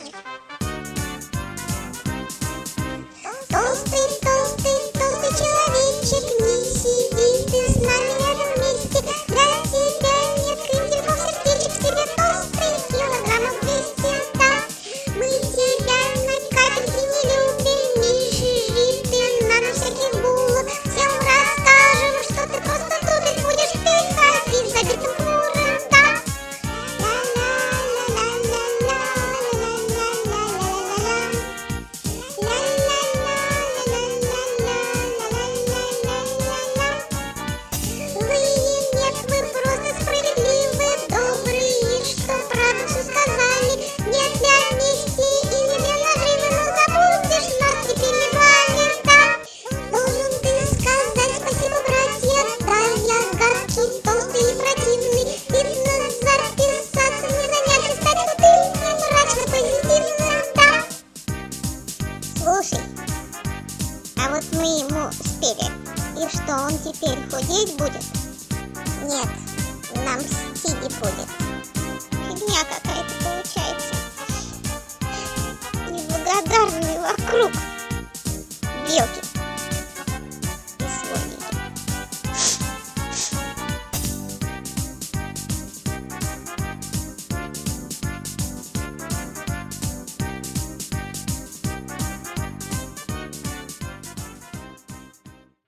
• Качество: 128, Stereo
детский голос